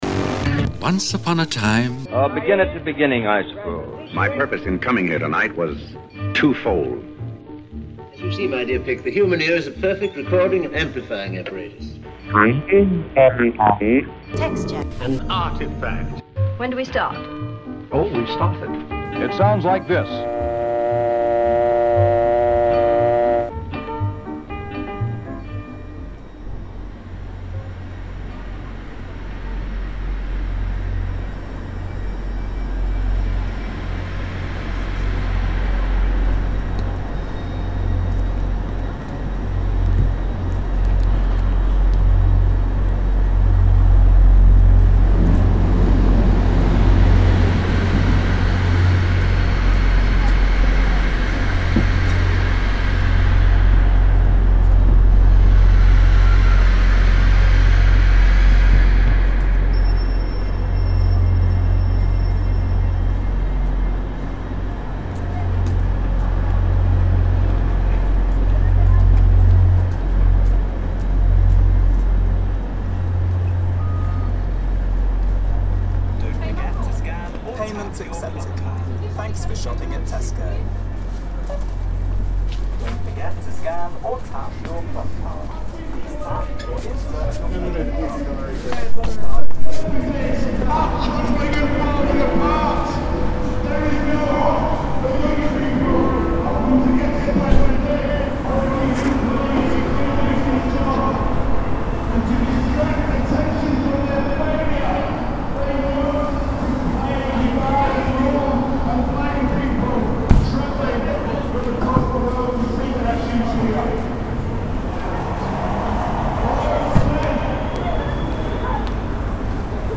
Nine protests, three strikes and one vigil London, Cambridge & Glasgow November 2022 to September 2024 Please listen to this soundscape on headphones Lecturer strike meeting outside UCL, 15th November 2022 Lecturer strike meeting outside Birkbeck, UoL, 24th November 2022 Protest in Gower St, Bloomsbury, 30th November 2022 Unidentified protest from a distance, London, 16th…